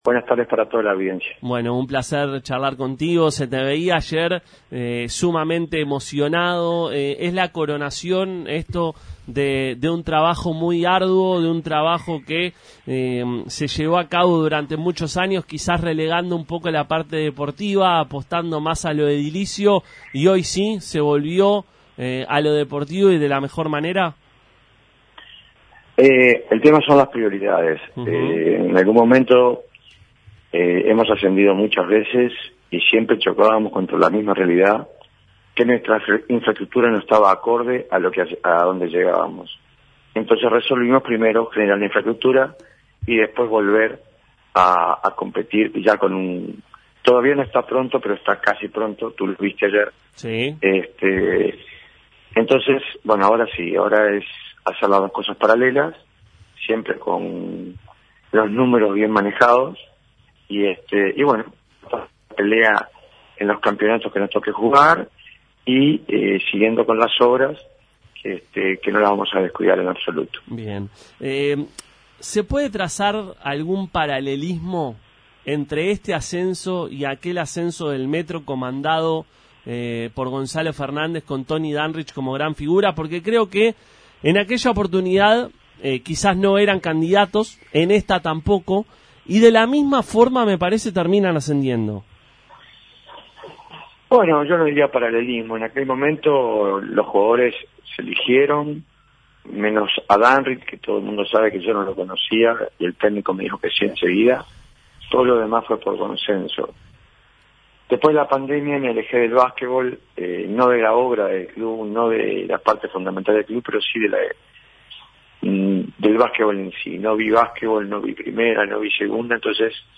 pasó por los micrófonos de Pica la Naranja y habló del ascenso del equipo a El Metro.